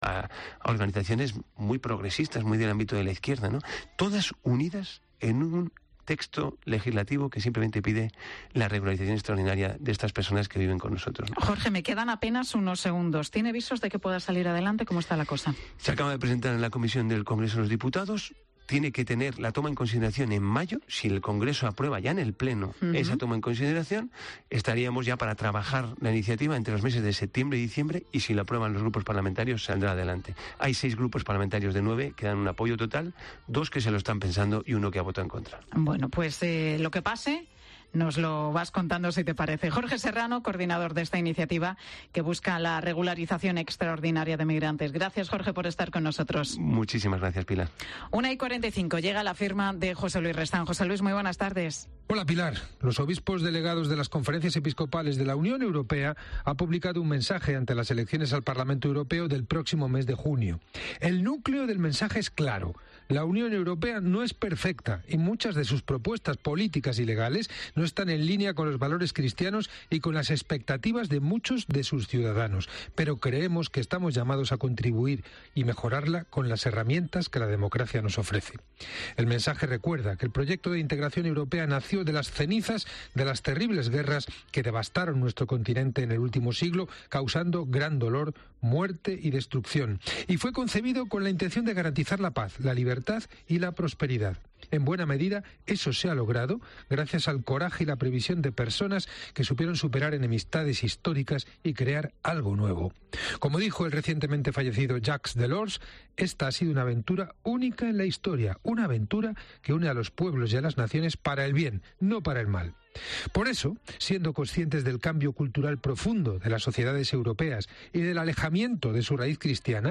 Recibimos a Borja Verea, líder del PP en Santiago, para hablar sobre los contratos del Ayuntamiento de Santiago en época de pandemia para la contratación de mascarillas y sobre el parque comarcal de bomberos. También te contamos cuál es la mejor hamburguesa de España